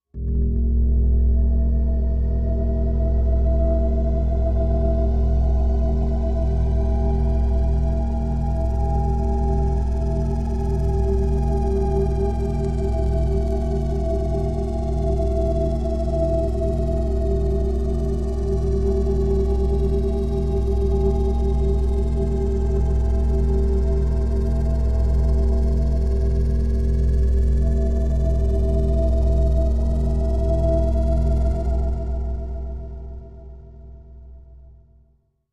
Suspended Slow Pulse Suspended Motion